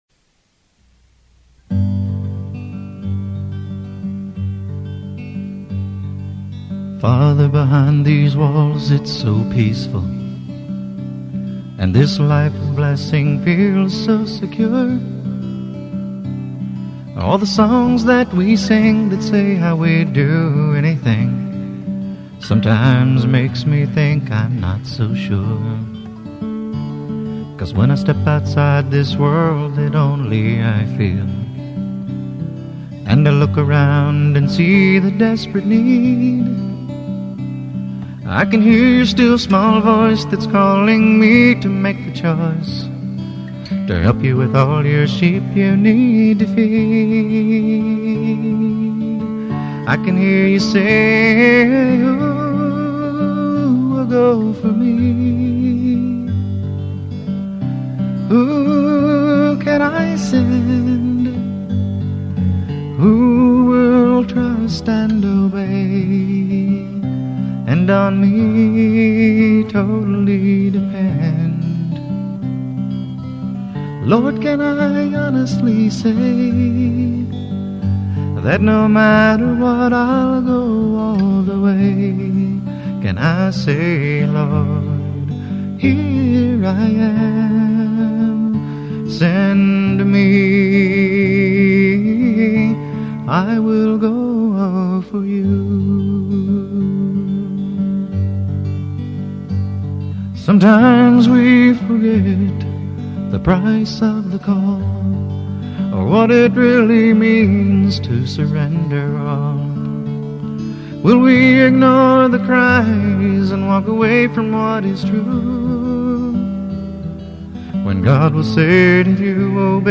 acoustic ballad
with a full band
I played the guitar and sang lead vocals